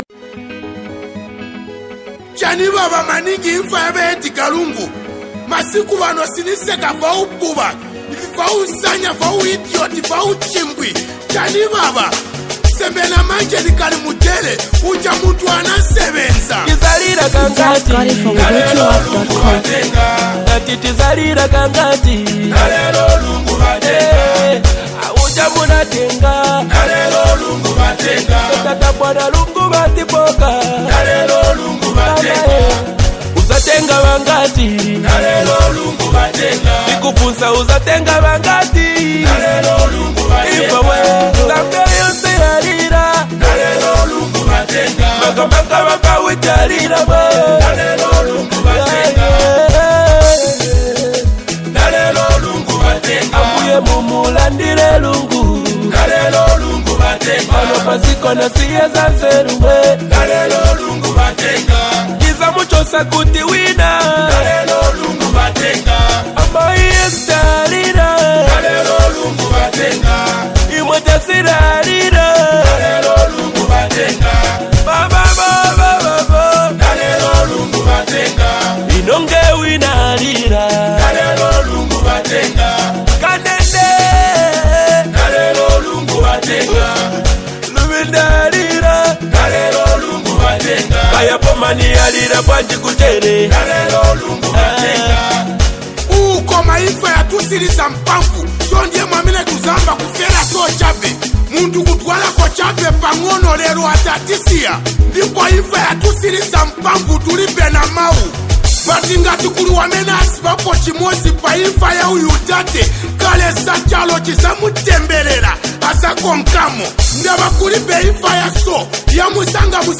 Zambian Music